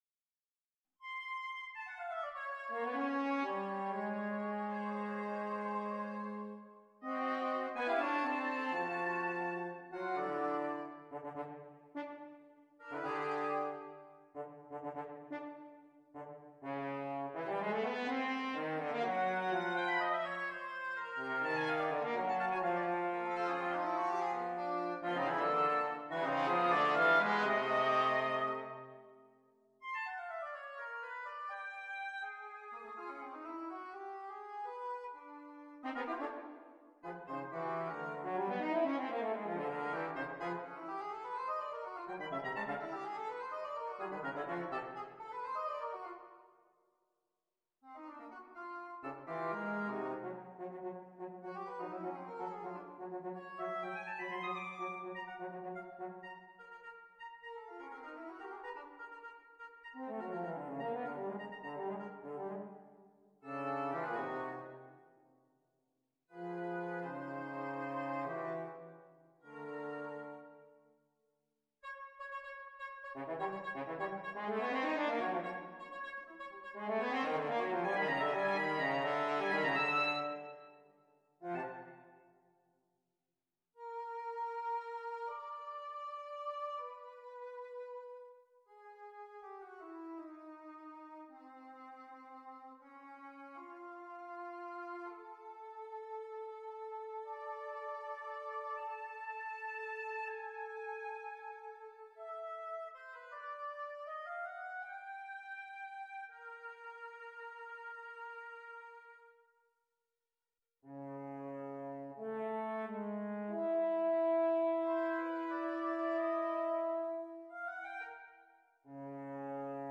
Double-ReedSonatinaOp87.mp3